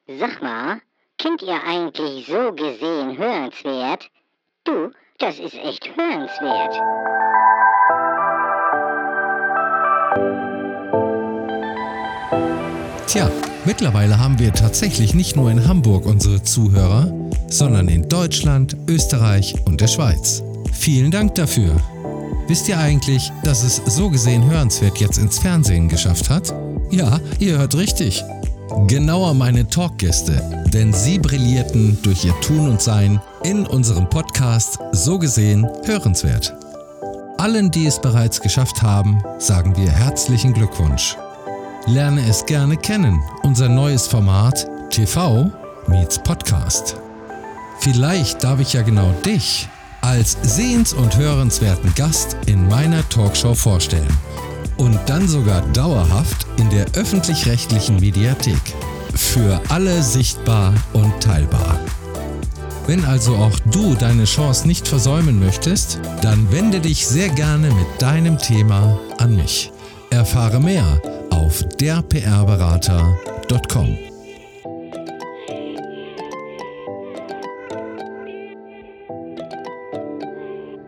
Sag-mal-Werbespot-Der-PR-Berater_mixdown.mp3